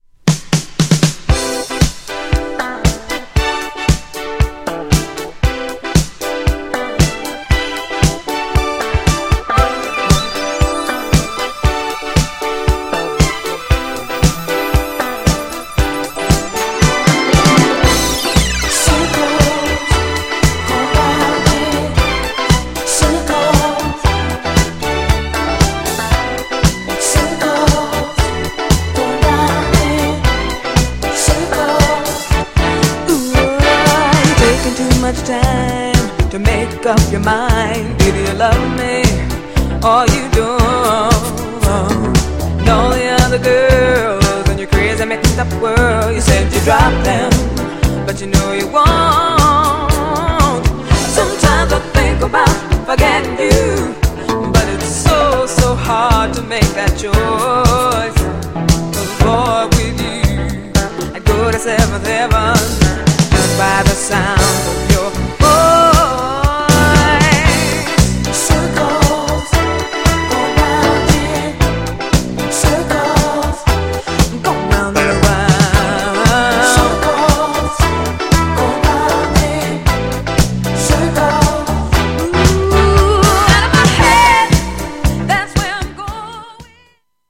GENRE Dance Classic
BPM 76〜80BPM